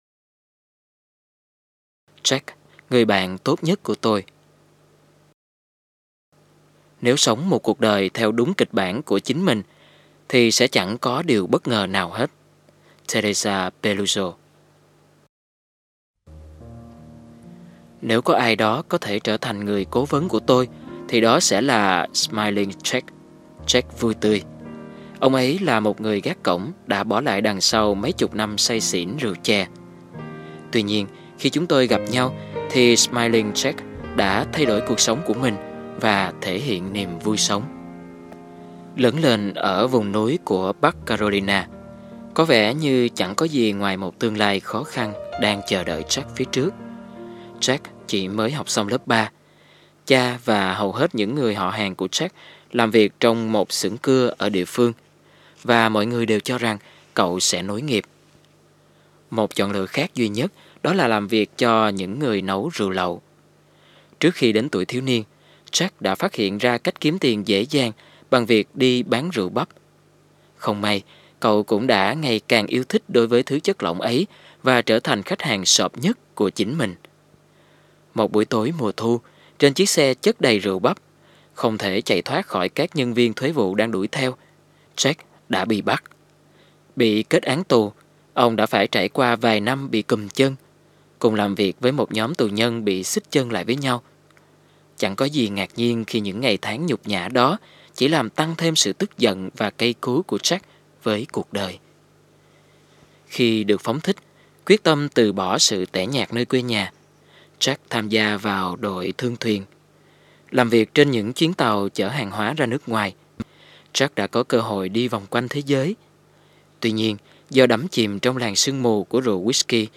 Trang Audio Books